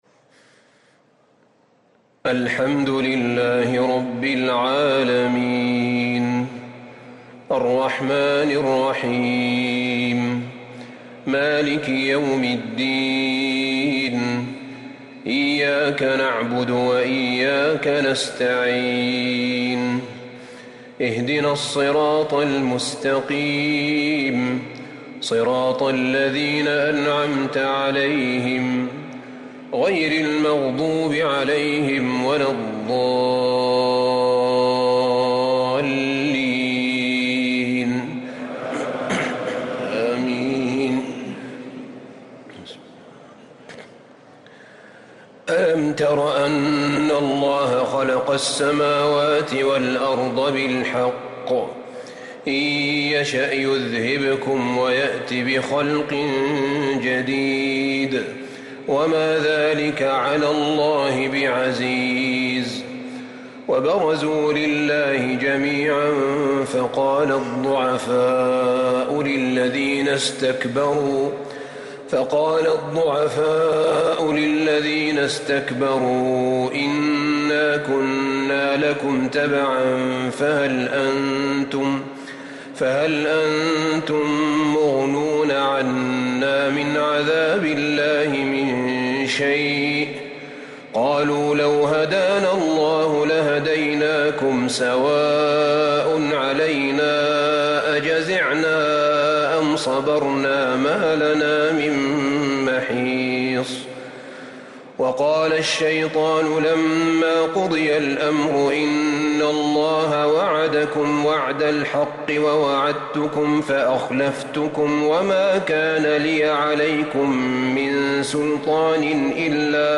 تراويح ليلة 18 رمضان 1444هـ من سورتي إبراهيم (19-52) و الحجر كاملة | Taraweeh 18 th night Ramadan 1444H Surah Ibrahim and Al-Hijr > تراويح الحرم النبوي عام 1444 🕌 > التراويح - تلاوات الحرمين